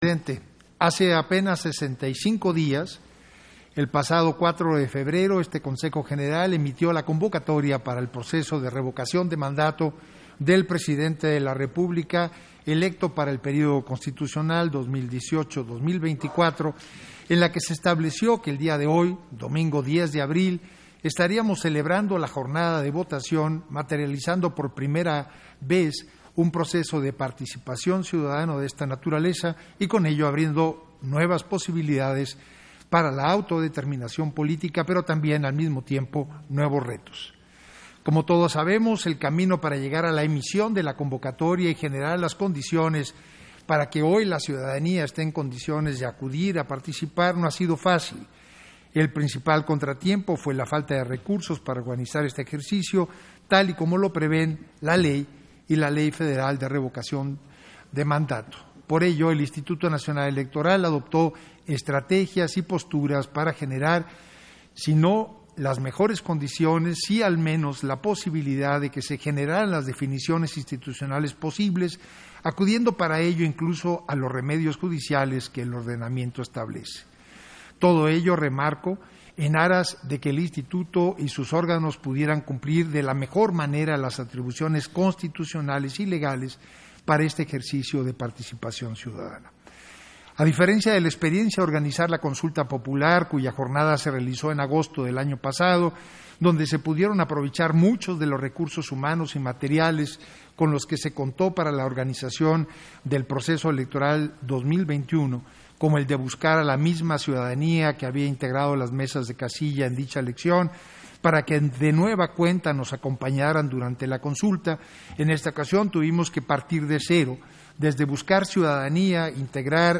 Intervención de Edmundo Jacobo Molina, relativo al informe sobre la instalación e inicio de Consejos Locales y Distritales, con motivo de la jornada de Revocación de Mandato